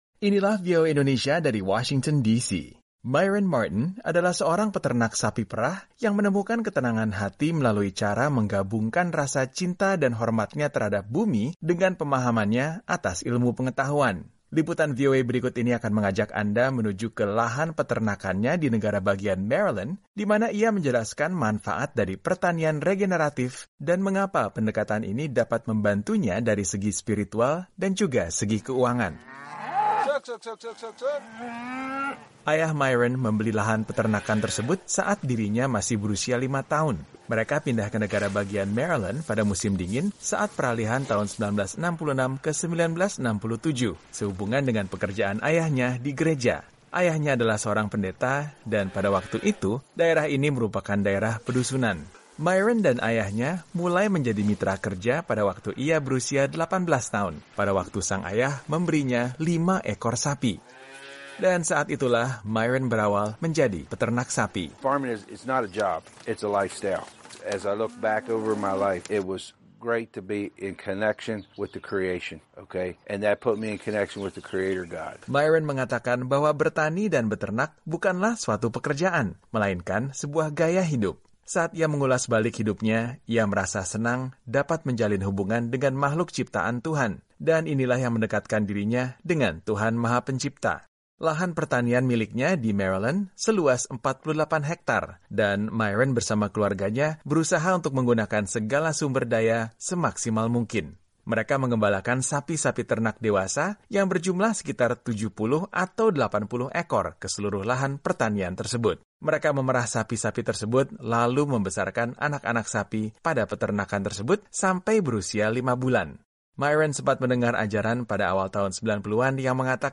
Seorang peternak sapi perah menemukan ketenangan hati dengan menyatukan cinta dan hormatnya terhadap bumi dengan pemahamannya atas ilmu pengetahuan. Laporan VOA ini akan menjelaskan pemahamannya atas pertanian regeneratif yang membantunya dari segi spiritual sekaligus keuangan.